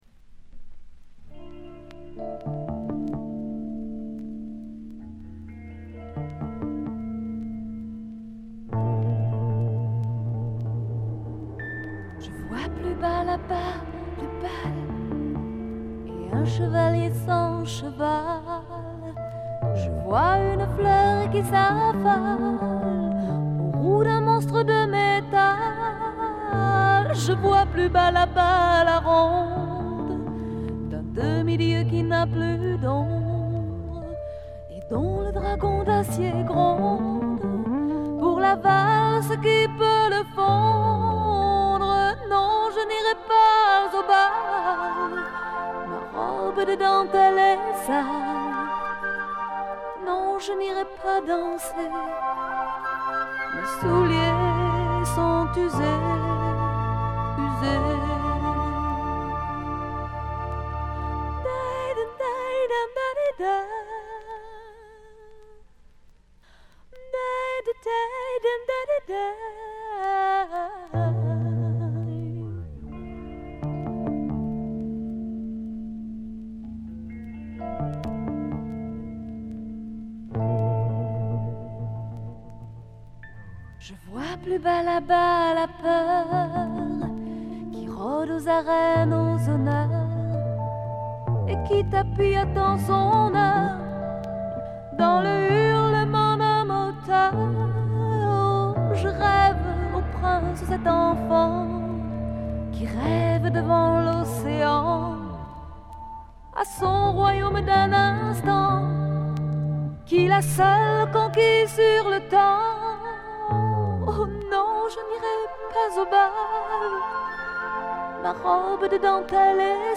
部分試聴ですが静音部でのバックグラウンドノイズ、チリプチ。
70年代から80年代にかけて数枚のアルバムを発表しているフランスの女性シンガー・ソングライター。
フレンチポップ好盤。
試聴曲は現品からの取り込み音源です。